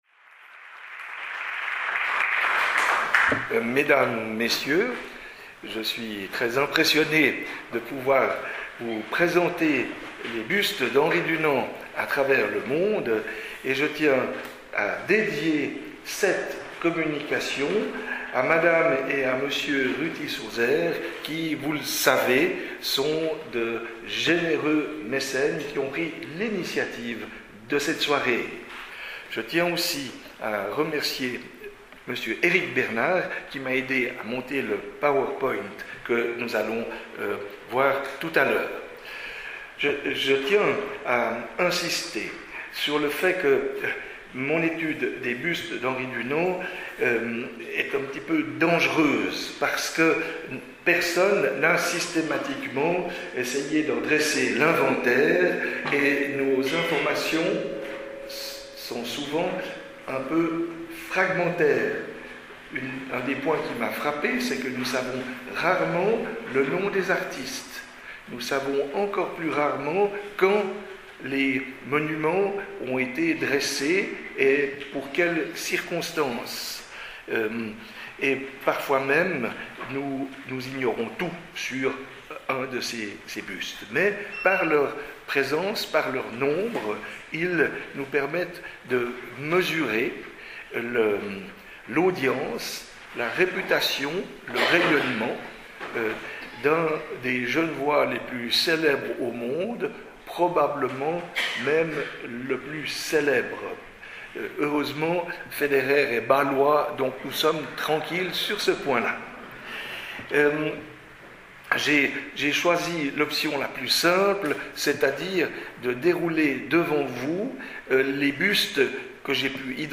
écoutez la conférence Enregistrement réalisé le jeudi 20 octobre 2022 au Palais de l'Athénée